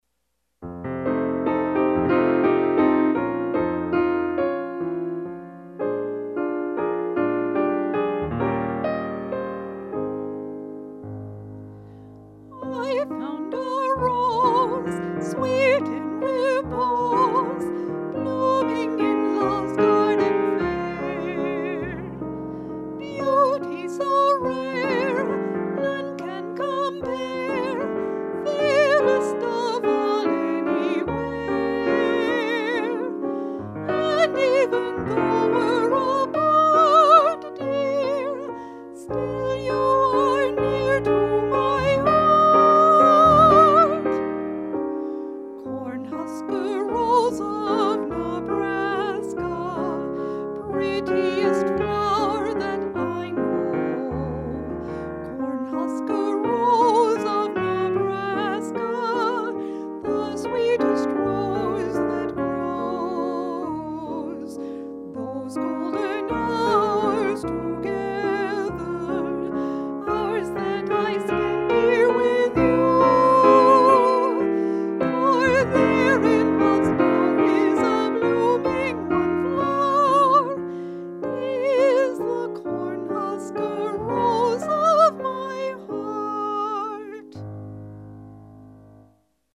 voice and piano